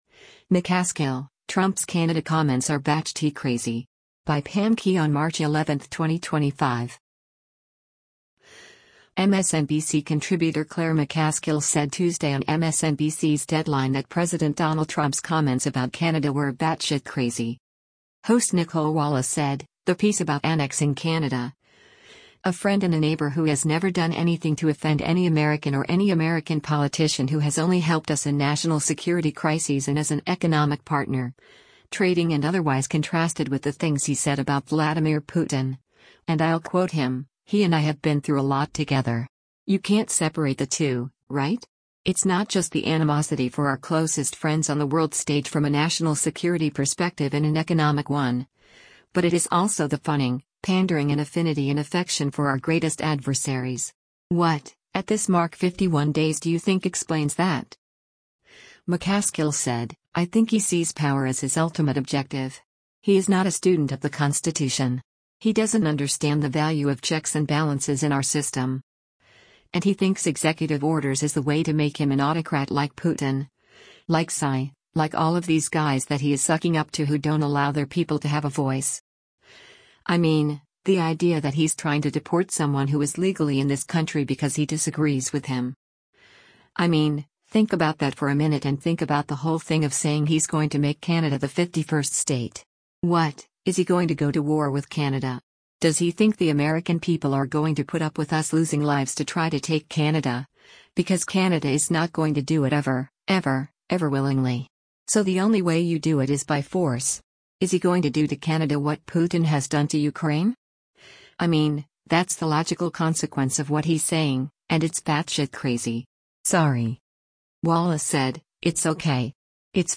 MSNBC contributor Claire McCaskill said Tuesday on MSNBC’s “Deadline” that President Donald Trump’s comments about Canada were “batshit crazy.”